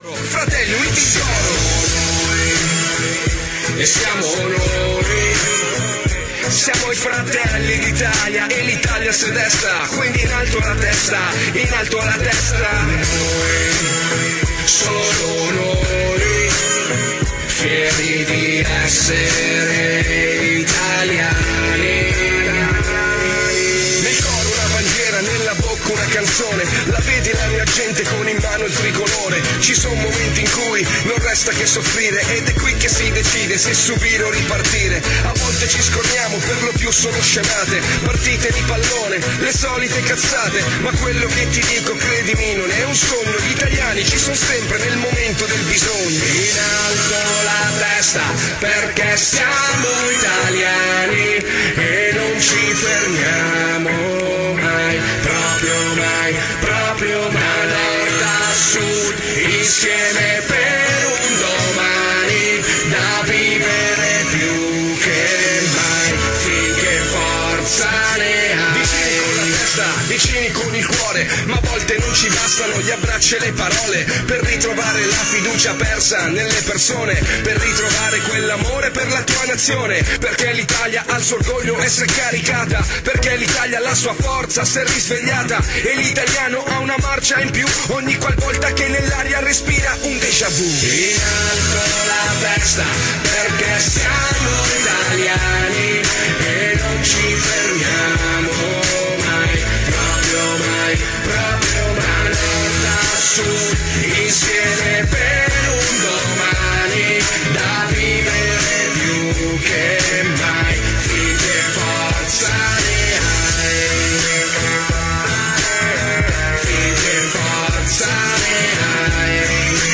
Di seguito il messaggio audio del Sindaco Sergio Pirozzi del 06 APRILE 2017